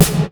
LMMS Linn snare with lots of reverb and then compressed in Audacity,
to sound like an '80s gated snare.
linn_snare_gated.flac